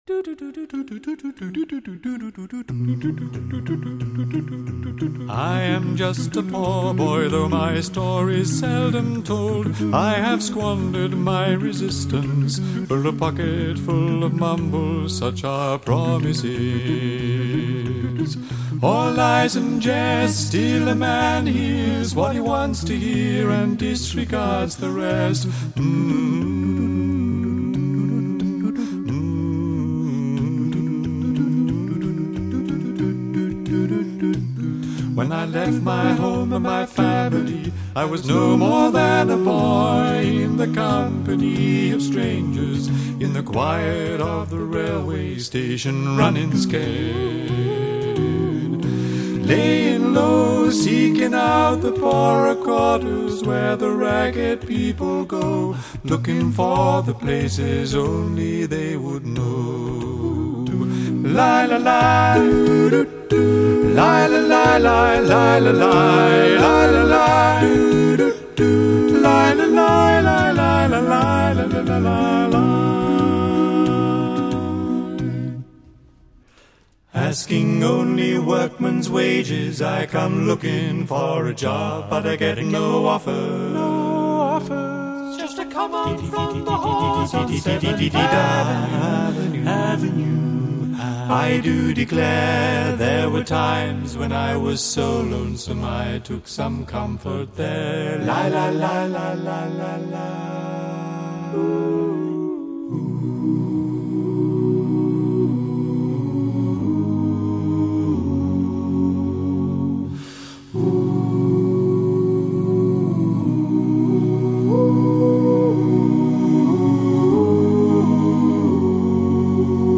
世界顶级无伴奏清唱组合完美演绎跨界经典
用人声模仿各种乐器，惟妙惟肖。
CD1 流行篇